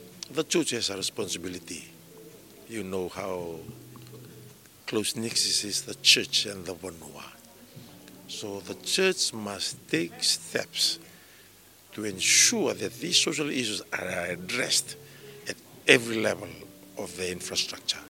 Speaking at the Lomaiviti Provincial Council meeting in Suva yesterday, Siromi Turaga says marijuana is no longer a major concern as people are now resorting to hard drugs.
Attorney General Siromi Turaga.